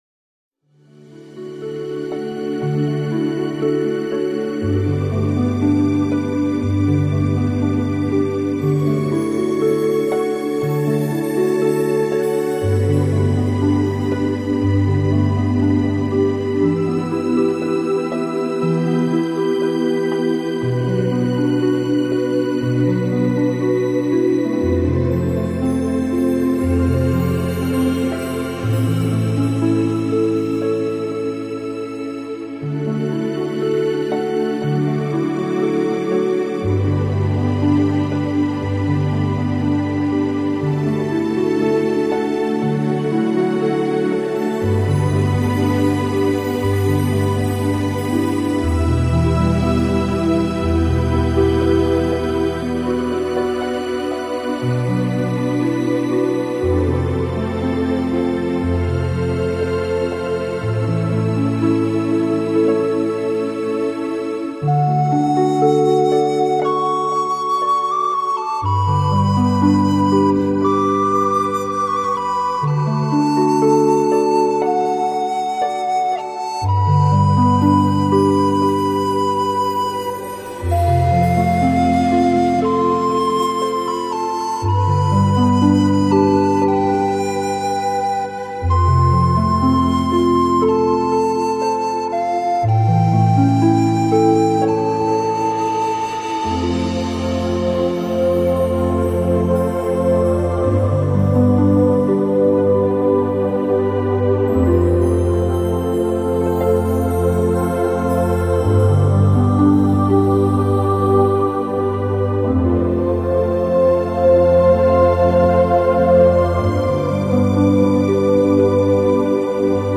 断的刺激著脑部的平衡，音乐的速度与心跳的节奏极为接近，大大的提升了身心的放松程度。